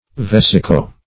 Search Result for " vesico-" : The Collaborative International Dictionary of English v.0.48: Vesico- \Ves"i*co-\ A combining form used in anatomy to indicate connection with, or relation to, the bladder; as in vesicoprostatic, vesicovaginal.